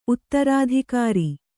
♪ uttarādhikāri